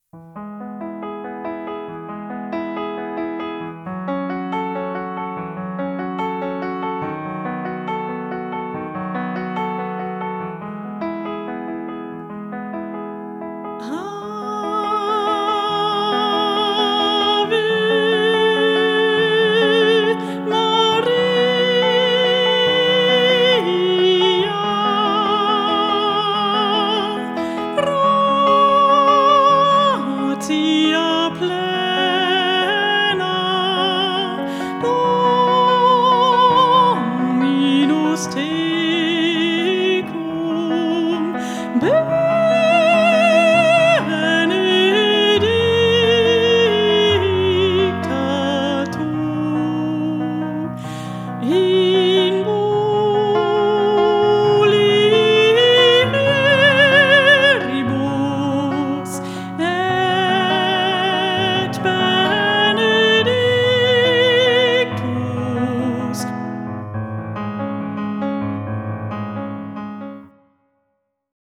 Gesang, Klavier und mehr. Sängerin und Pianistin spielen bei Hochzeiten, Jubiläen, Empfängen u.v.m.